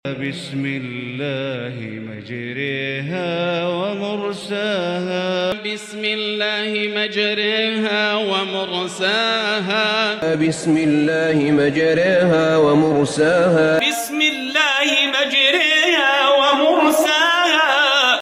Imālah is a Tajwīd rule where the alif sound is tilted toward a yāʼ, producing a sound between “ā” and “ē”. It appears in certain words like "majrāhā", which is recited as "majrēhā" in Ḥafṣ ‘an ʿĀṣim via the Shāṭibiyyah route.